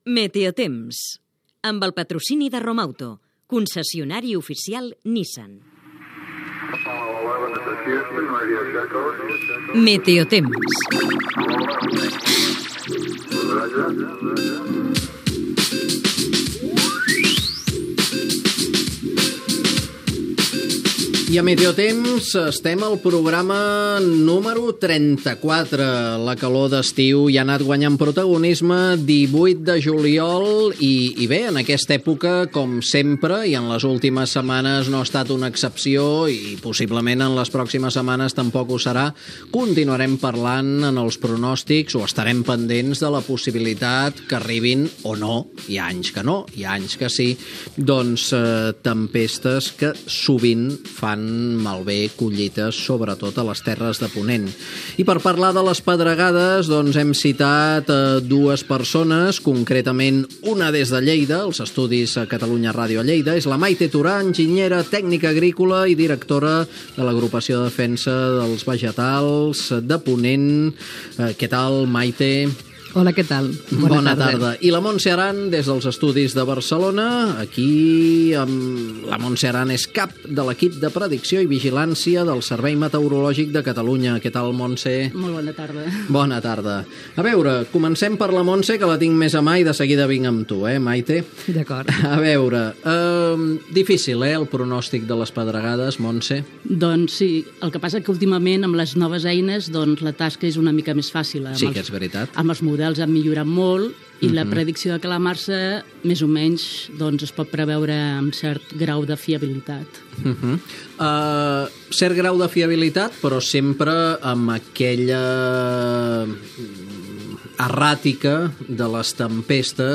Divulgació
Presentador/a